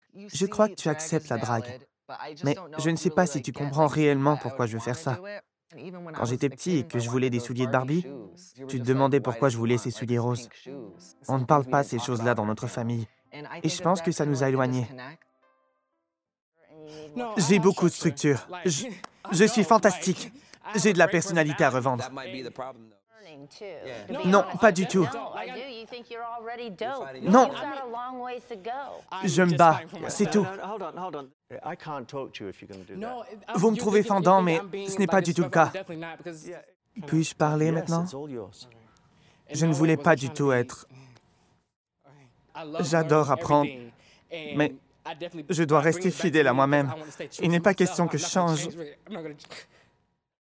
Voice over Voice Overlay demo 2025